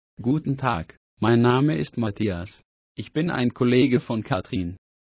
Sprecher für das Vorleseprogramm MWS Reader